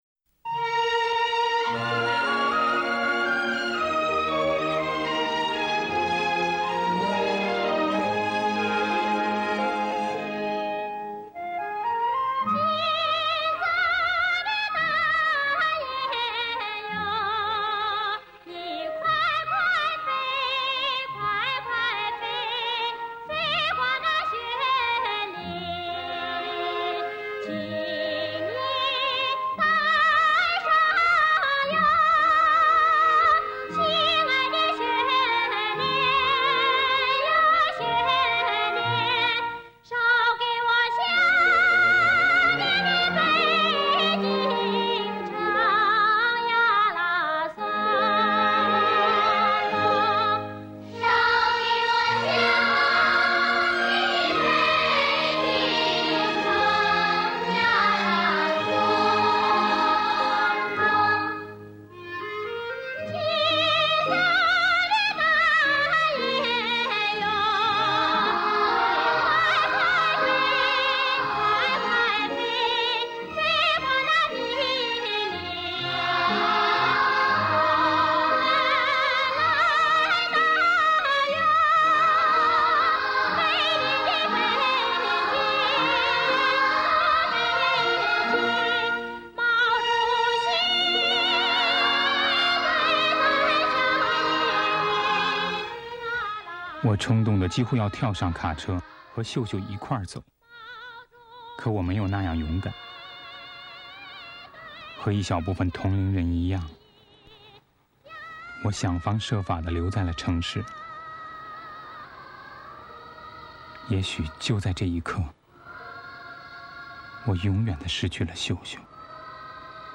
类别:电影原声
仿民谣的音乐风格、高亢却刺耳的演唱、机械化的节奏
当年的文革歌曲为反映工农品味，大部份改编自民谣或以仿民谣风写成，加入机械化的节奏感，听起来相当刺耳。